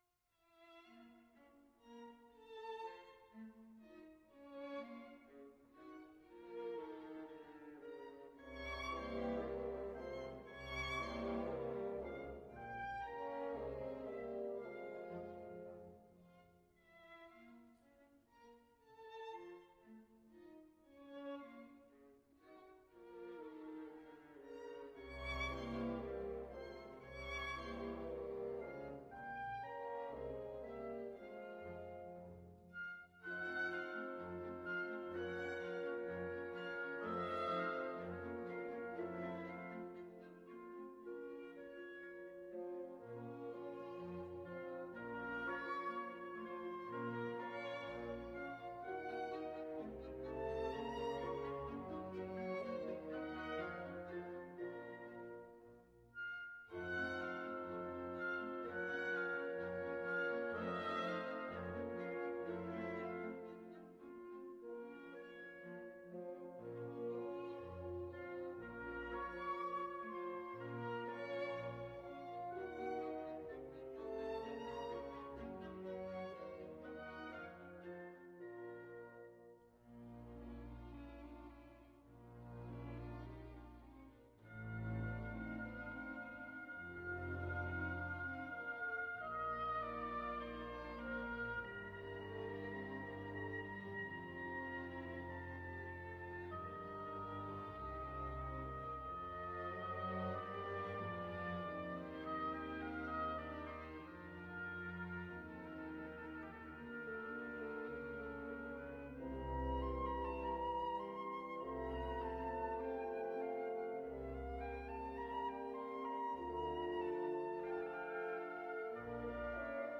Symphonie concertante en si bémol majeur